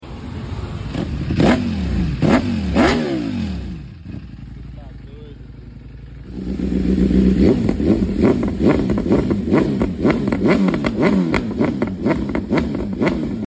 Which bike exhaust is better?